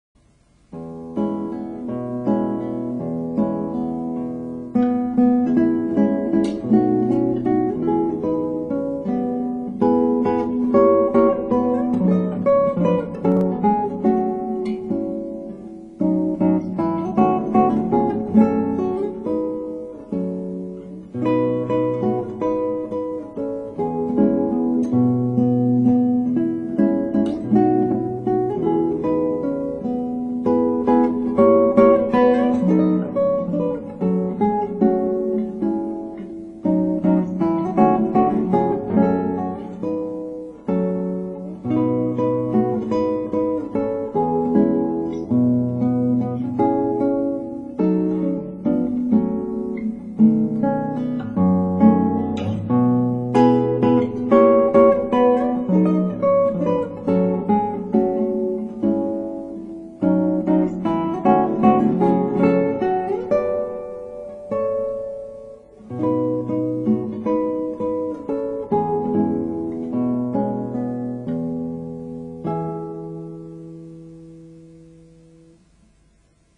クラシックギター　ストリーミング　コンサート
これ、結構古い録音なのです。
・・・タルイ曲調ですねぇ。